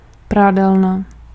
Ääntäminen
IPA : /ˈlɔːn.dri/